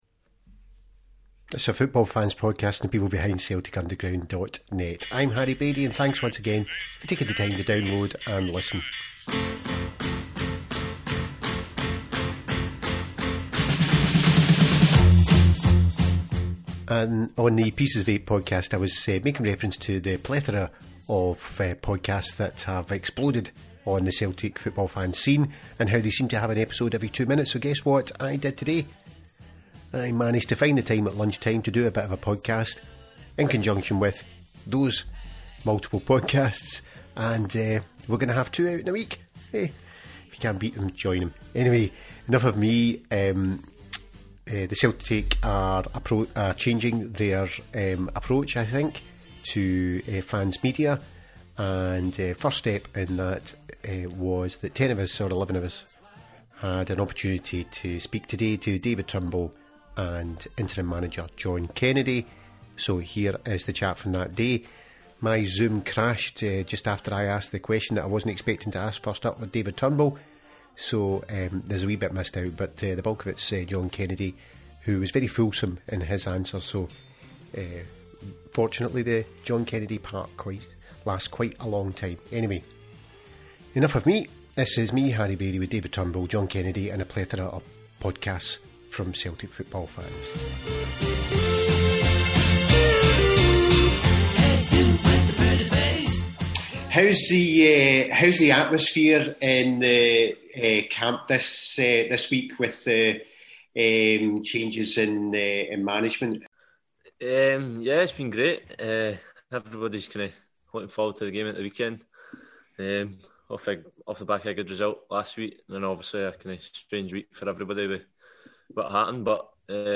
Celtic are looking to engage better with the fan media and this was the first step in 2021 of that new engagement with a specially organised fan media press conference.